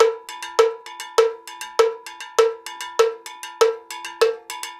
Index of /90_sSampleCDs/USB Soundscan vol.36 - Percussion Loops [AKAI] 1CD/Partition B/03-100STEELW